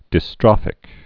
(dĭ-strŏfĭk, -strōfĭk)